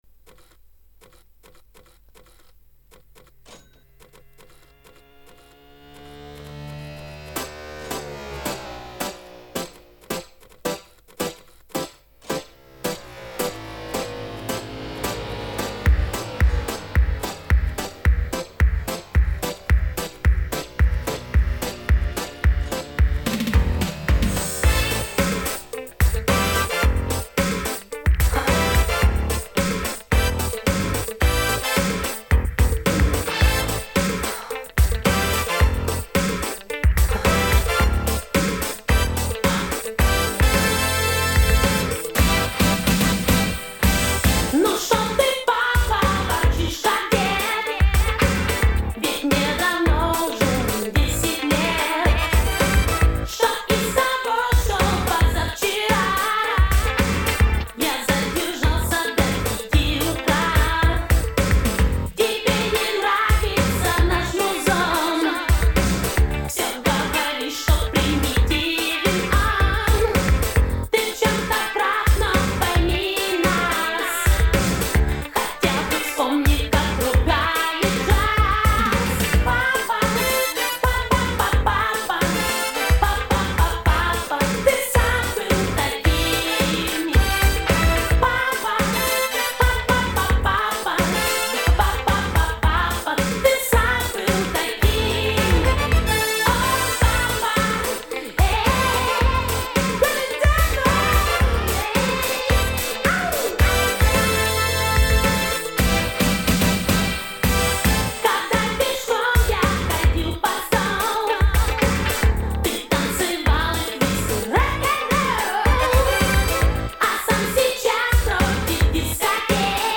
Я еще приложил обычную версию песни которя была на виниле.